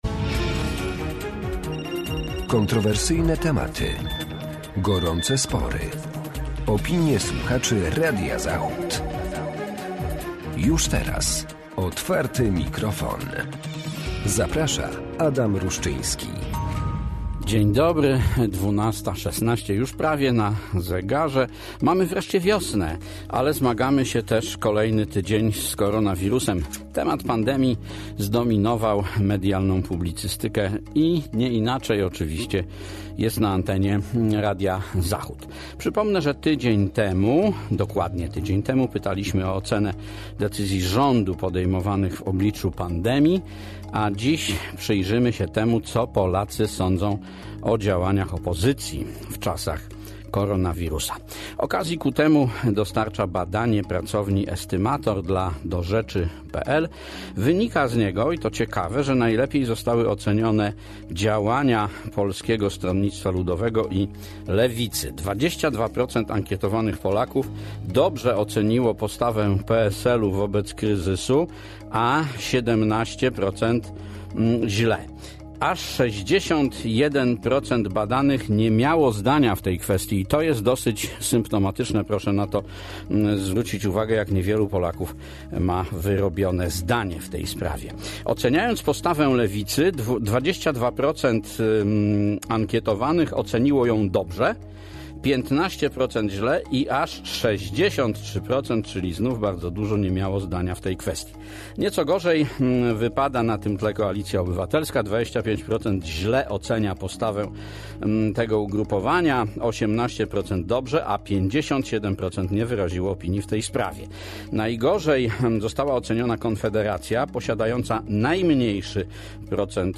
A jak oceniają postawę opozycji słuchacze Otwartego Mikrofonu Radia Zachód?